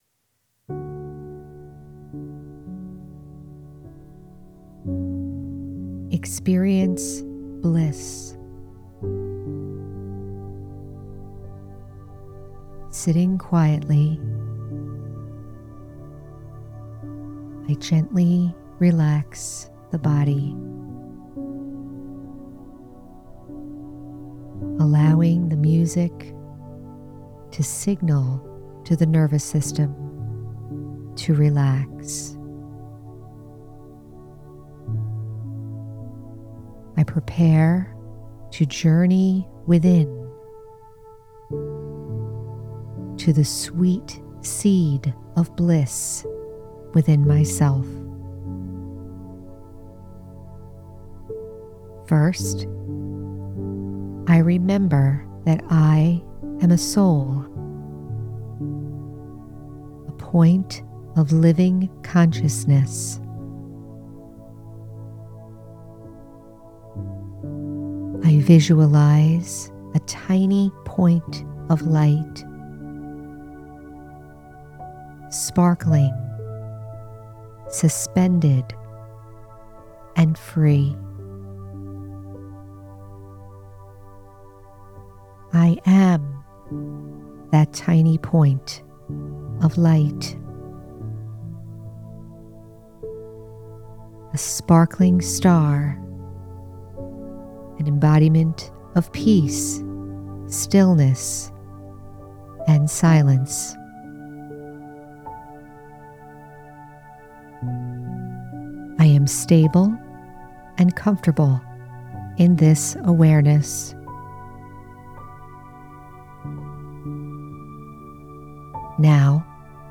Experience Bliss- Guided Meditation- The Spiritual American- Episode 162